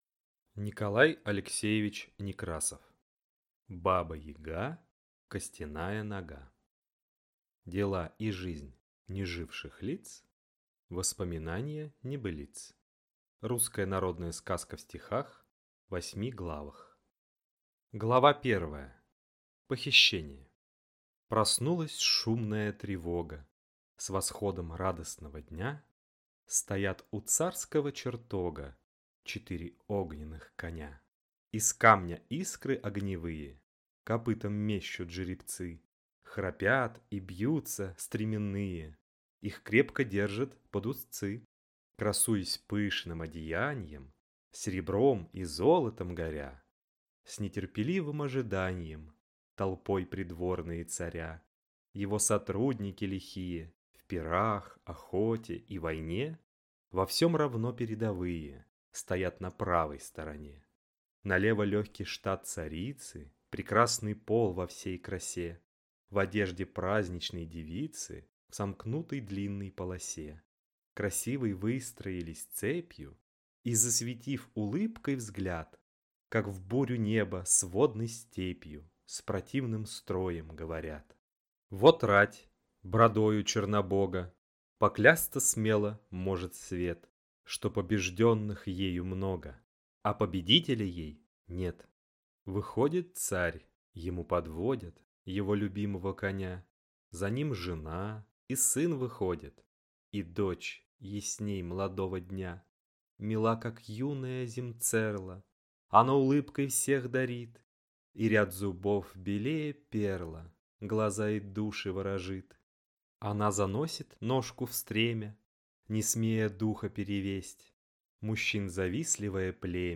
Аудиокнига Баба-Яга, Костяная Нога. Русская народная сказка в стихах. В осьми главах.